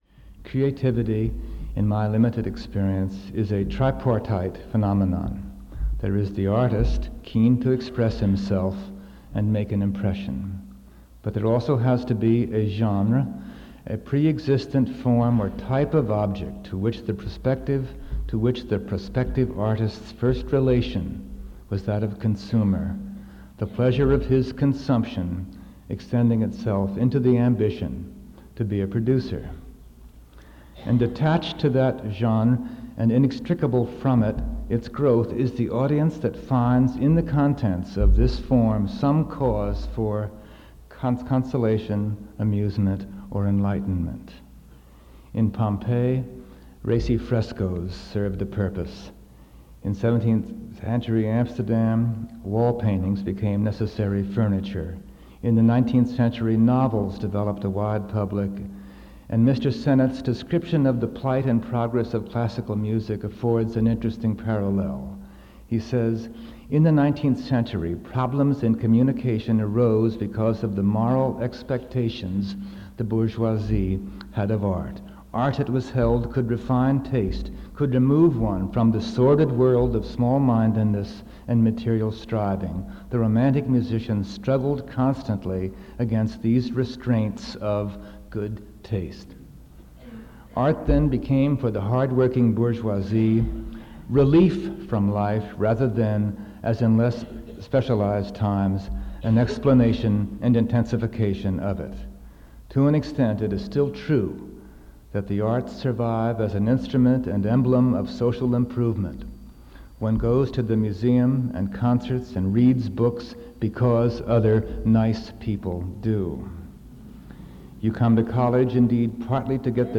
Updike delivered the Institute’s inaugural Herman Melville Lecture on the Creative Imagination on Thursday, April 25, 1985 in Page Hall on the University at Albany’s downtown campus. The title of his presentation was “Creativity and Response.”